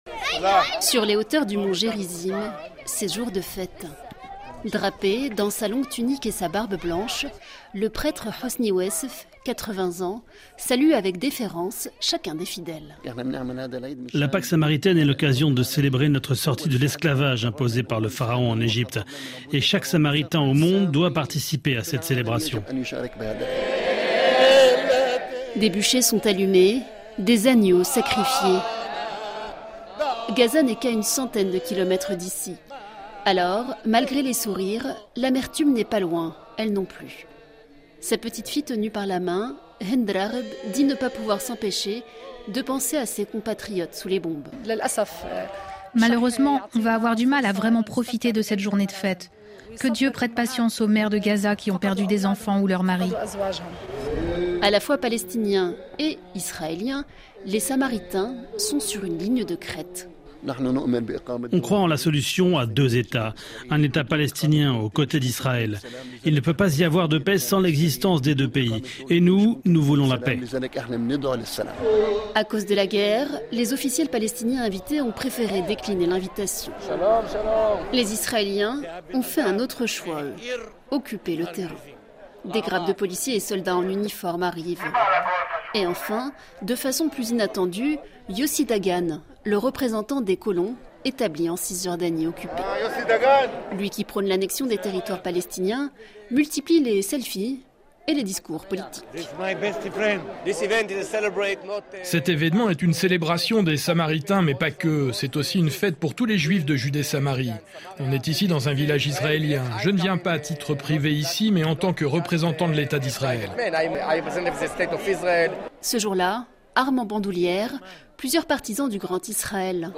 Chaque jour, l’illustration vivante et concrète d’un sujet d’actualité. Ambiance, documents, témoignages, récits en situation : les reporters de RFI présents sur le terrain décrivent le monde avec leur micro.